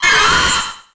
Cri de Corayôme dans Pokémon HOME.